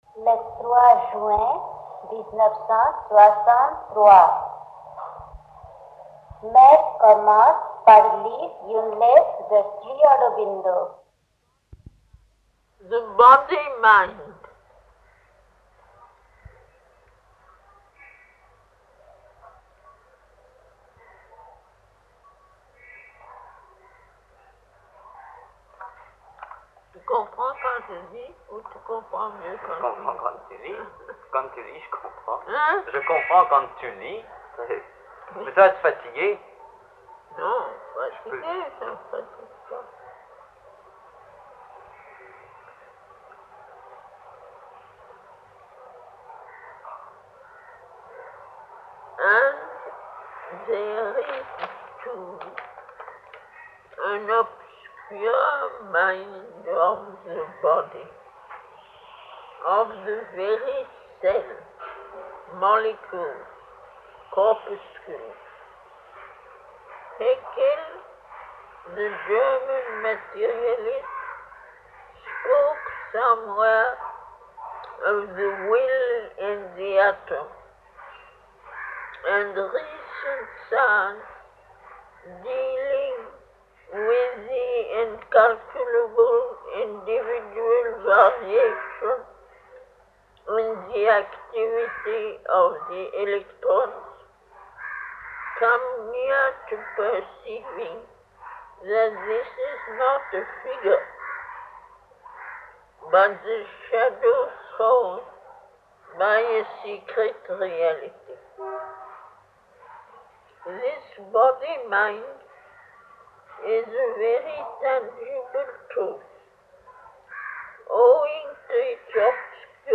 Recorded Conversations Year-1963 - Collected Works of Sri Aurobindo